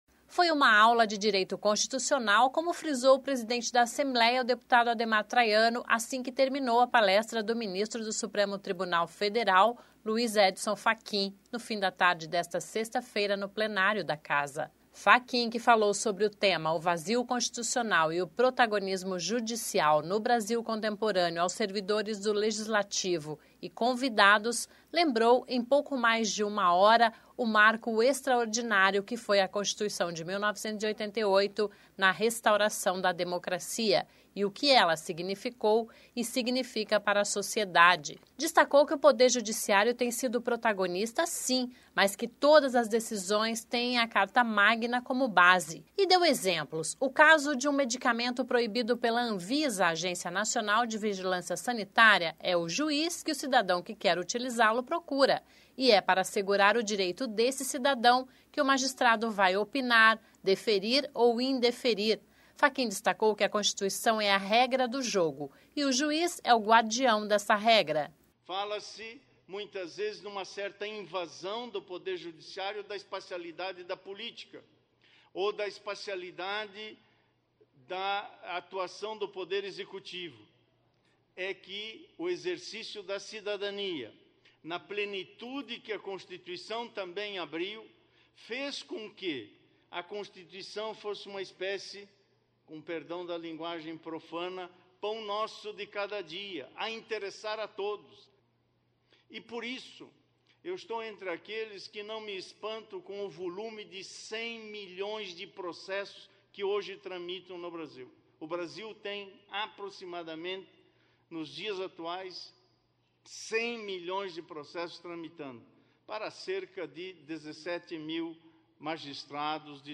Luiz Edson Fachin se emociona em palestra na Assembleia sobre Vazio Constitucional